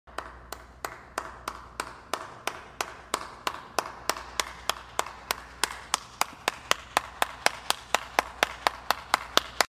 Аплодисменты в зале